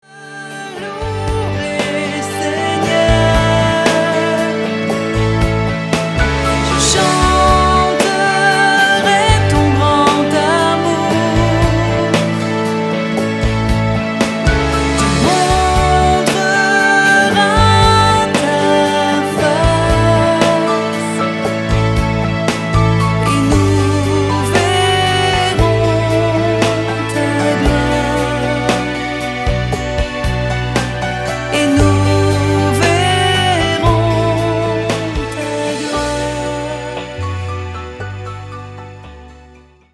allant du pop au rock en passant par des ballades douces